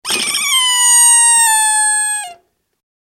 フェフェフェーン（48KB） ドドドー（70KB）
マルチメディアカード記録済み効果音12種類